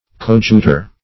Coadjutor \Co`ad*ju"tor\, n. [L. See Co-, and Aid.]